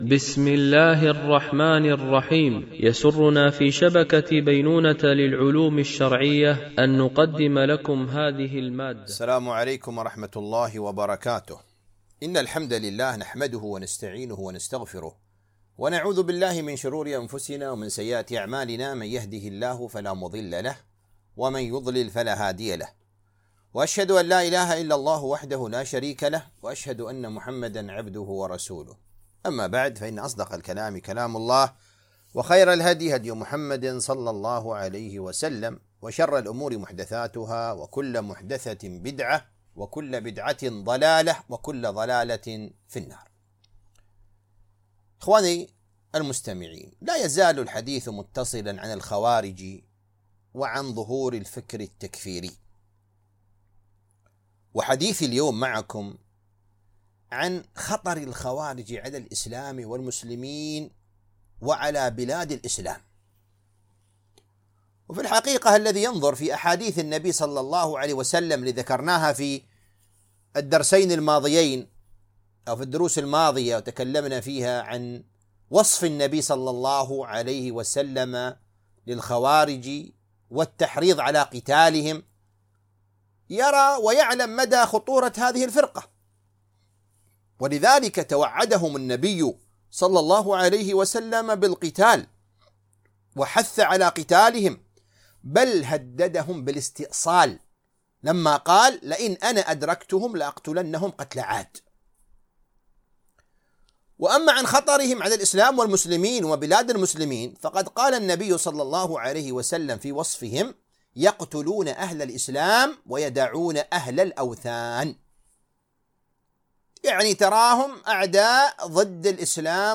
سلسلة محاضرات